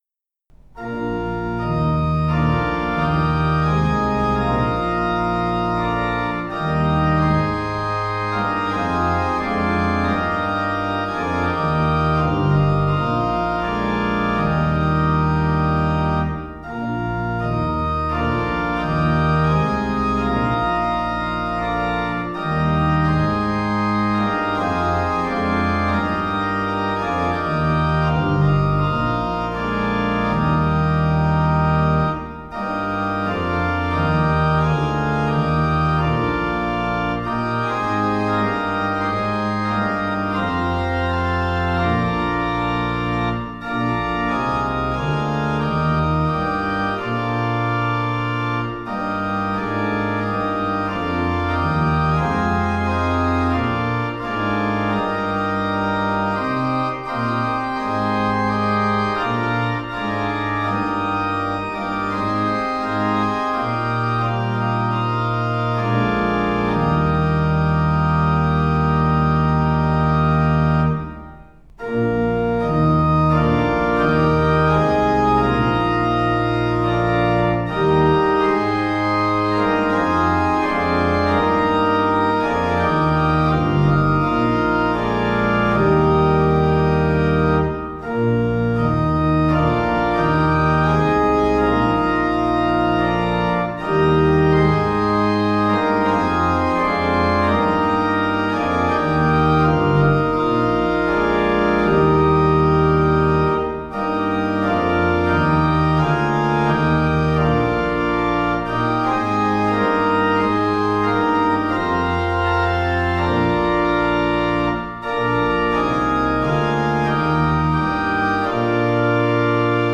Audio Recordings (Organ)
WS733-midquality-mono.mp3